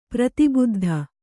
♪ prati buddha